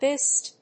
発音記号
• / pɪst(米国英語)